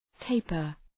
Προφορά
{‘teıpər}